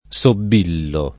sobillo [ S ob & llo ]